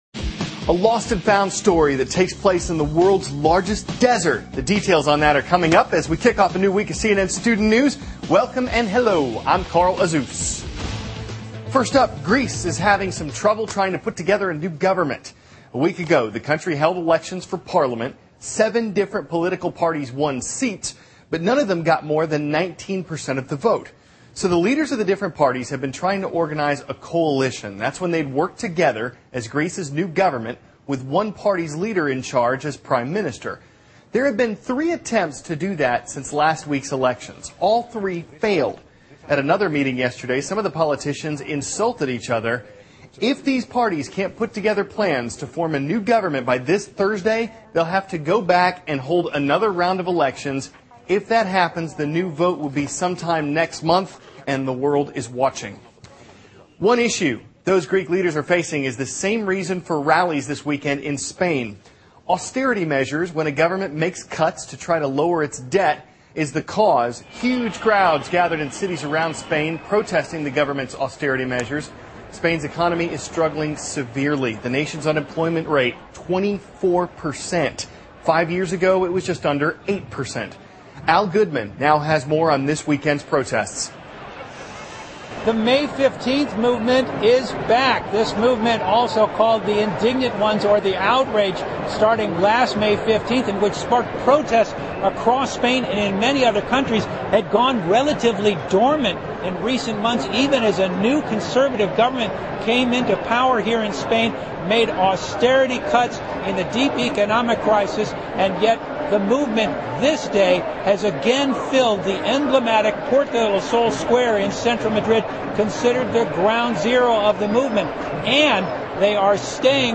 CNN News西班牙民众集会抗议政府财政紧缩政策